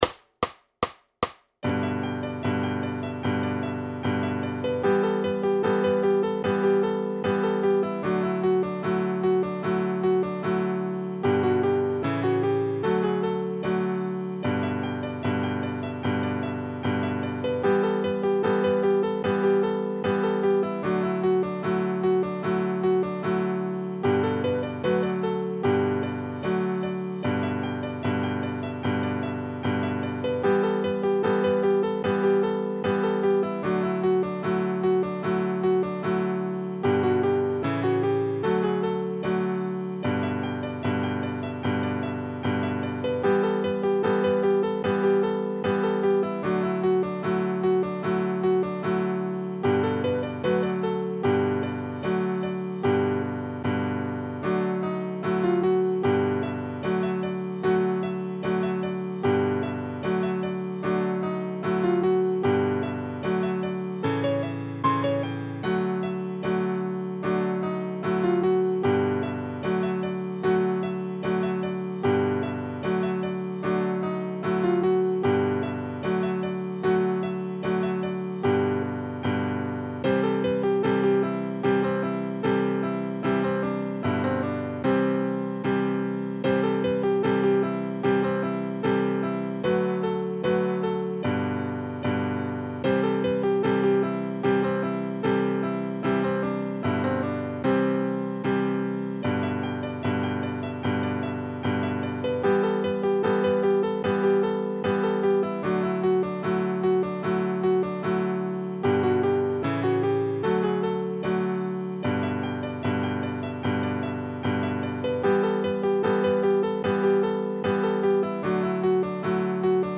Noty na snadný klavír.
Hudební žánr Ragtime